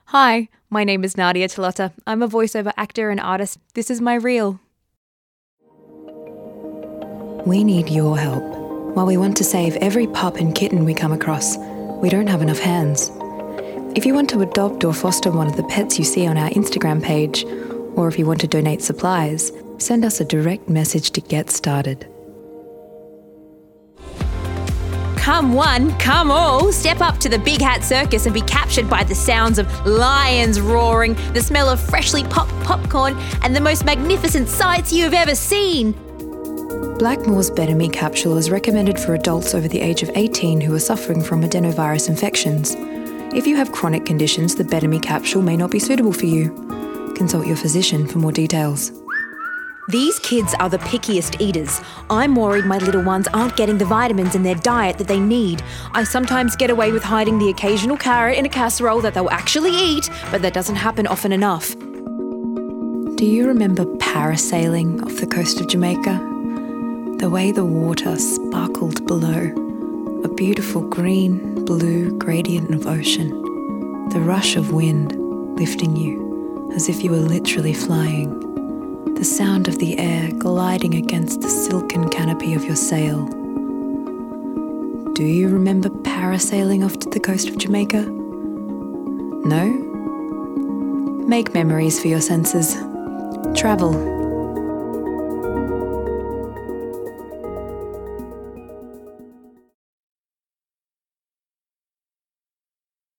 VOICE DEMO REEL